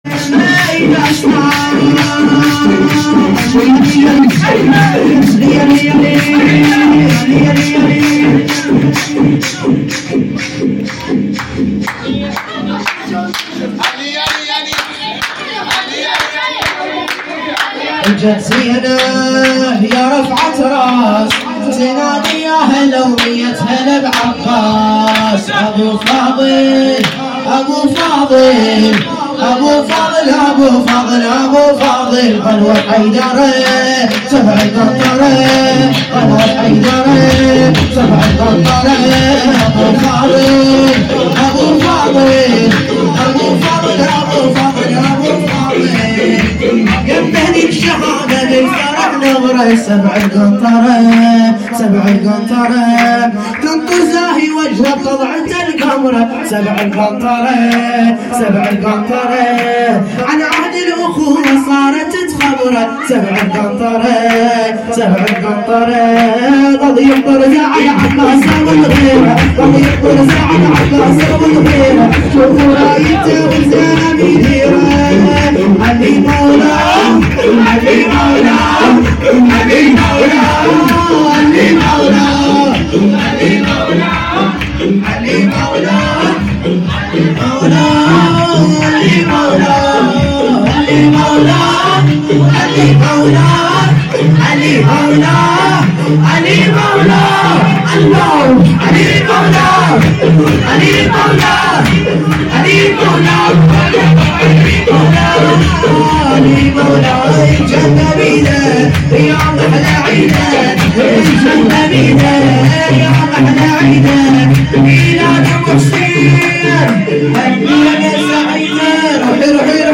مداح اهل بیت